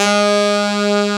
A SAX   2.wav